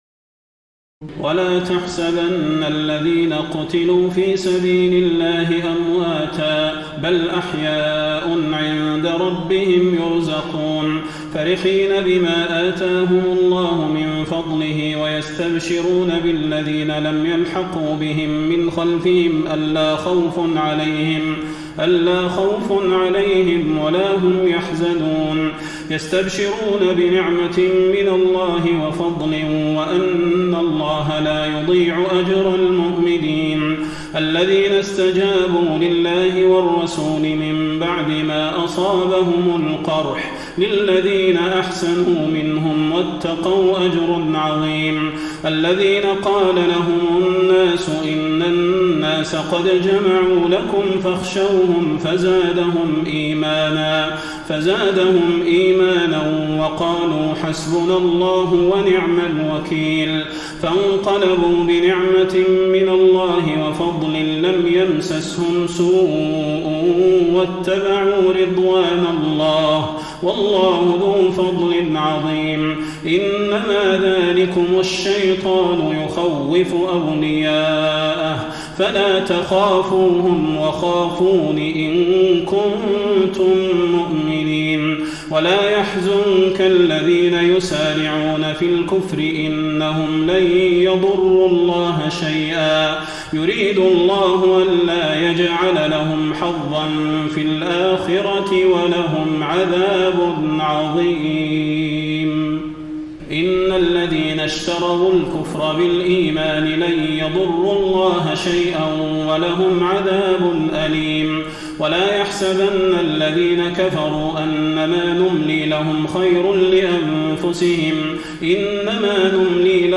تراويح الليلة الرابعة رمضان 1433هـ من سورتي آل عمران (169-200) و النساء (1-22) Taraweeh 4 st night Ramadan 1433H from Surah Aal-i-Imraan and An-Nisaa > تراويح الحرم النبوي عام 1433 🕌 > التراويح - تلاوات الحرمين